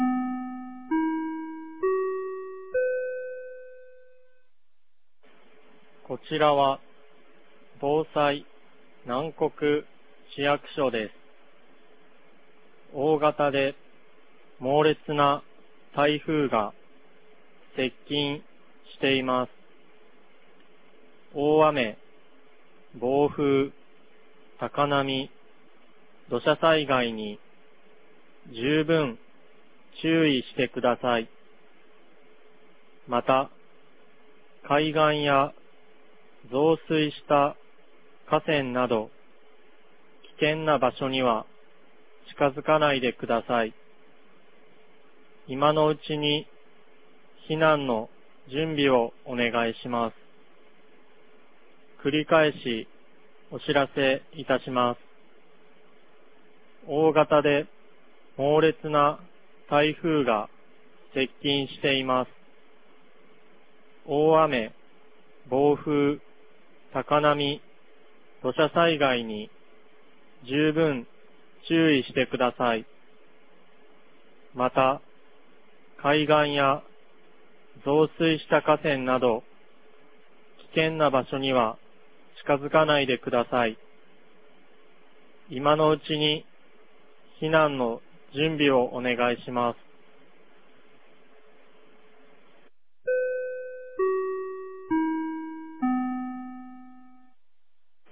2022年09月17日 16時46分に、南国市より放送がありました。
放送音声